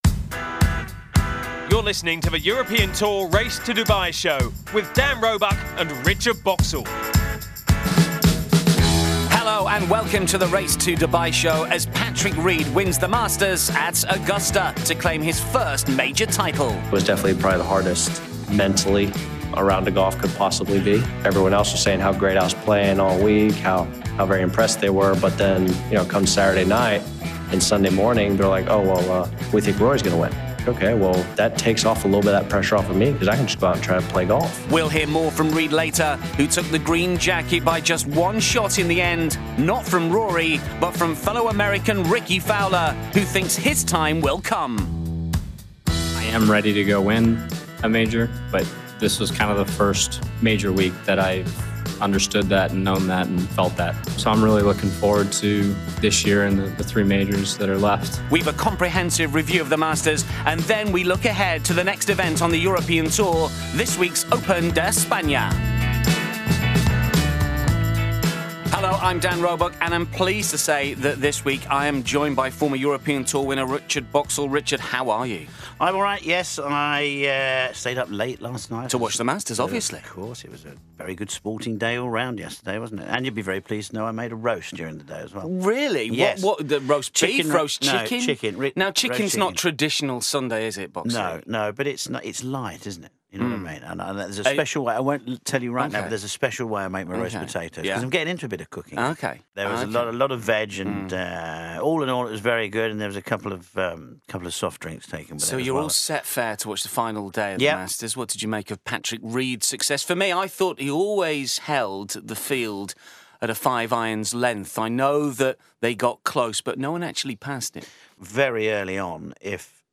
We hear from Reed, as well as his American compatriot Rickie Fowler, who was second. The trio also discuss Top 5 finishers Jordan Spieth, Jon Rahm and Rory McIlroy, as well as defending champion Sergio Garcia and Tiger Woods.